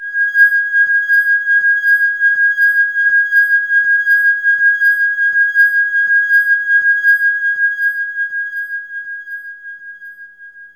WINE GLAS01L.wav